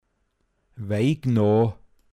Details zum Wort: Wäignå. Mundart Begriff für Salamander